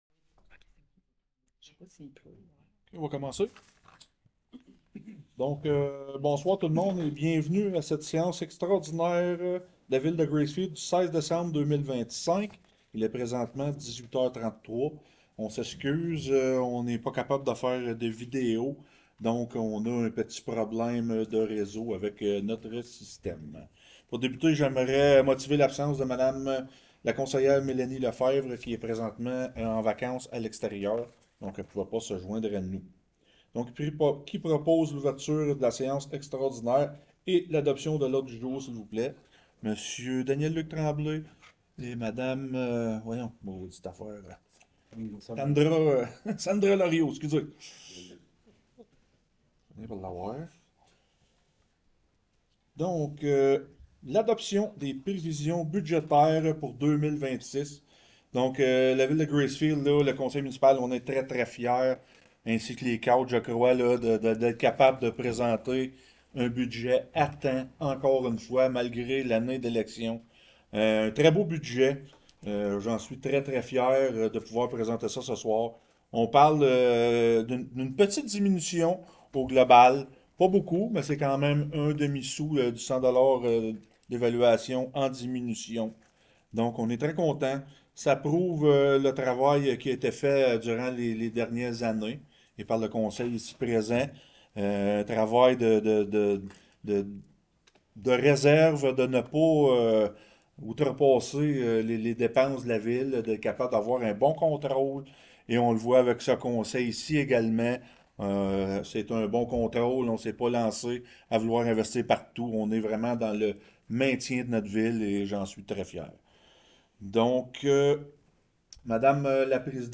Conseil | Séance extraordinaire